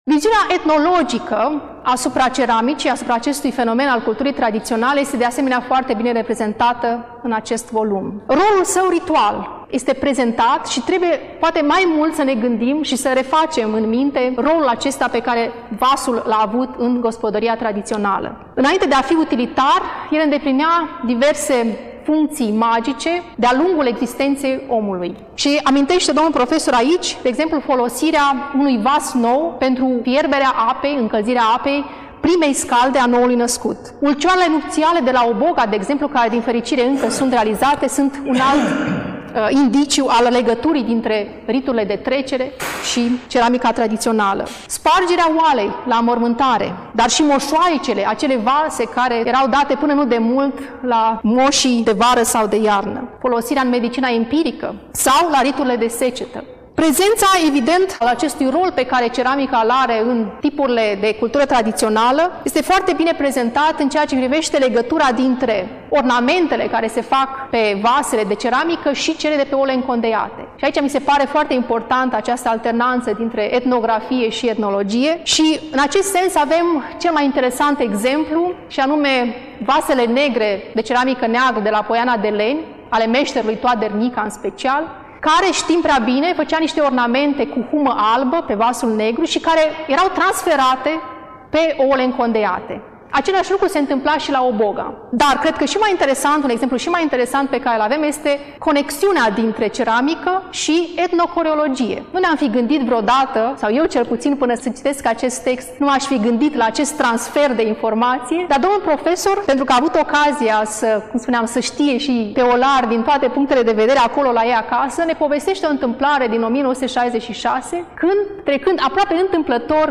Până la final, difuzăm discursul